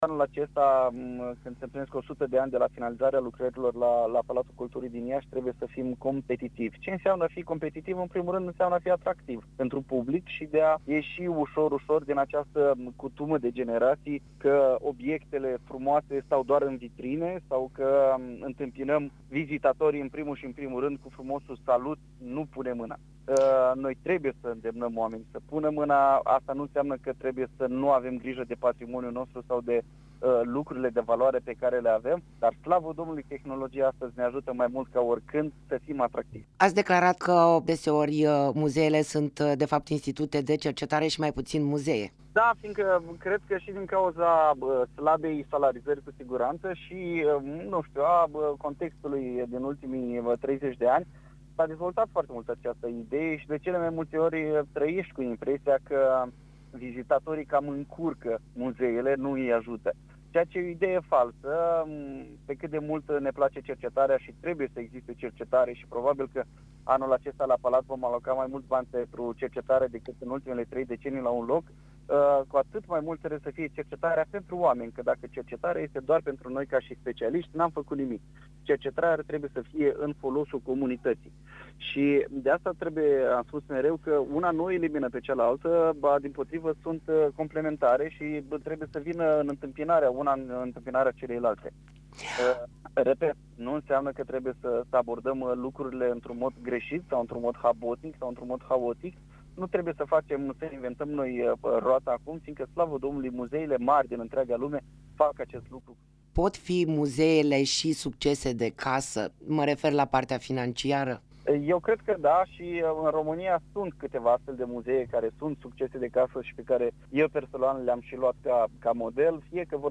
Interviu-Palatul-Culturii.mp3